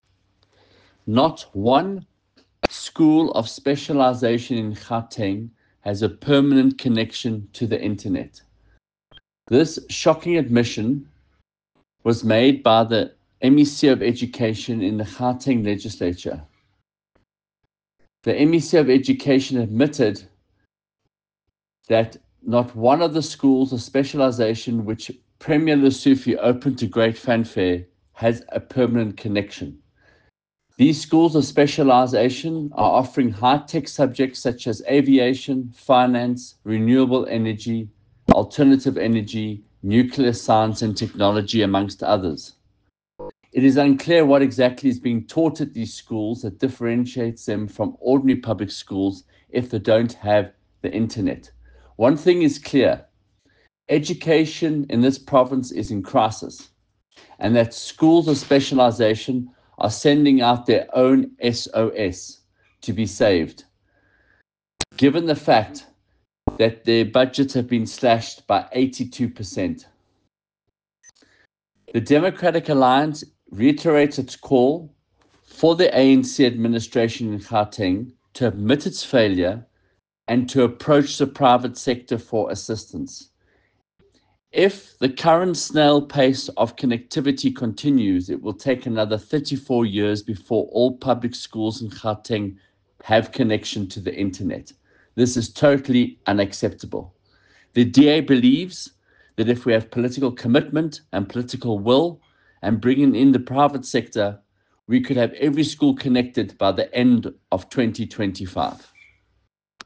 soundbite by Michael Waters MPL.